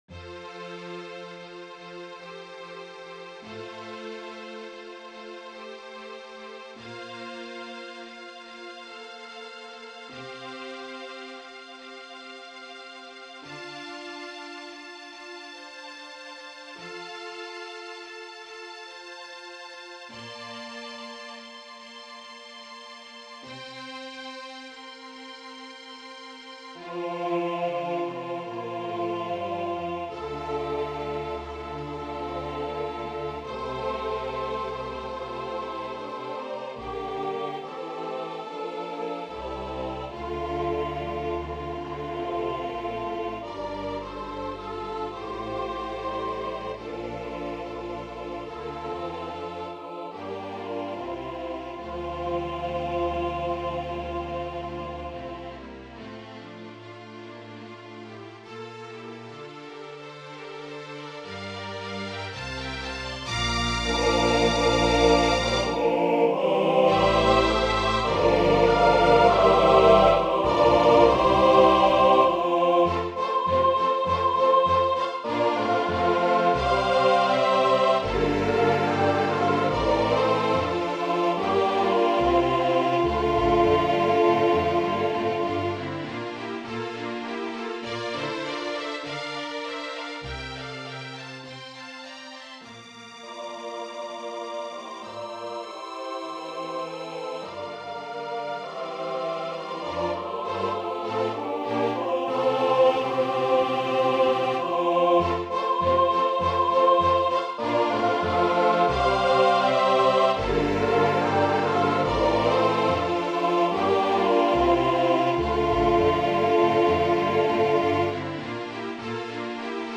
for SATB Chorus and Strings (2014)
(MP3 of SATB MIDI demo) / (MP3 of SSA performance)